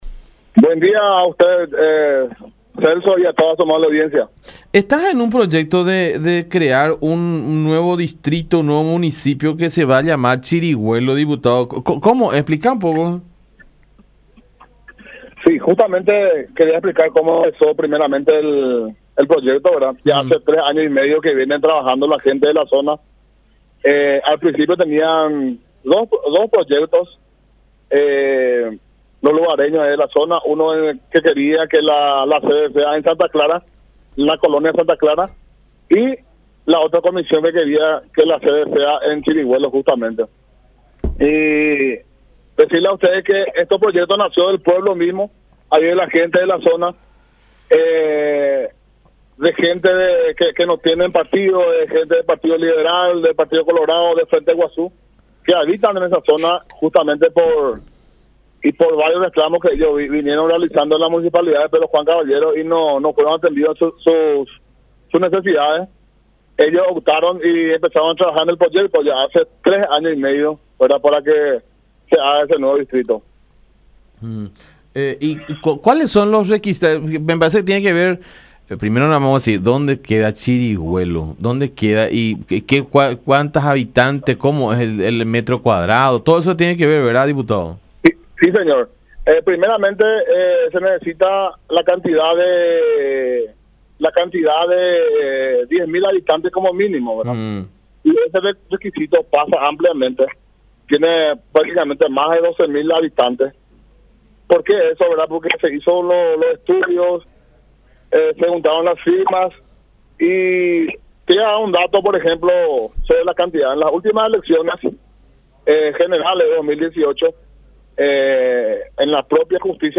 “Se viene trabajando desde hace 3 años y medio para el logro de este proyecto”, dijo el diputado Acosta en diálogo con La Unión, señalando que el principal motivo por el cual se ha planeado la creación del municipio de Chirigüelo es que los reclamos de los pobladores de este sitio no llegan a Pedro Juan Caballero, capital amambayense y ciudad a la que actualmente pertenece.